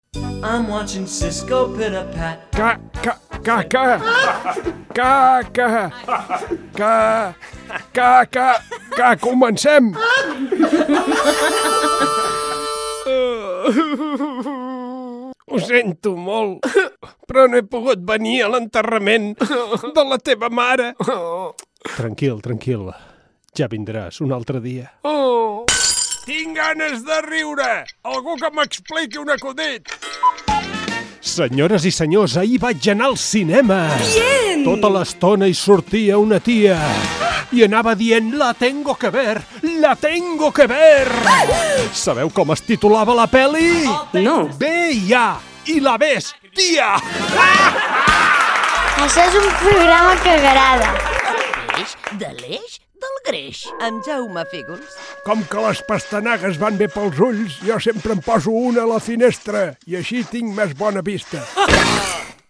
Miniespai humorístic: l'enterrament i una pel·lícula amb identificació del programa
Es tractava d'un minut d'humor amb frases absurdes, acudits, trucades de broma, paraules i expressions amb doble sentit... Tot ben guarnit amb molts efectes de so, ben picat i amb moltes veus diferents.